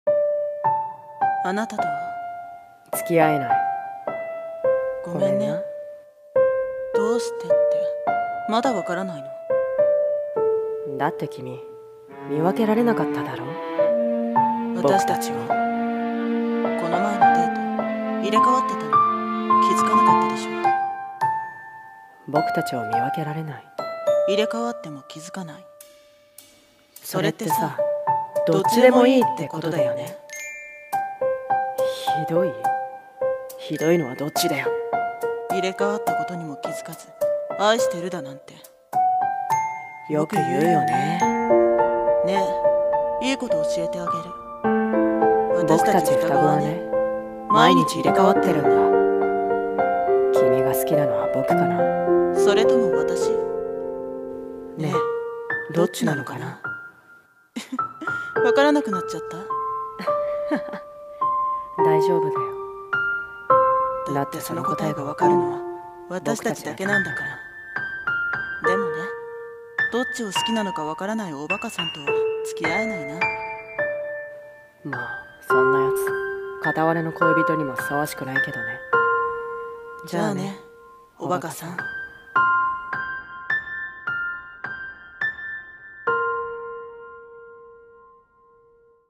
【二人声劇】双子遊戯【狂気】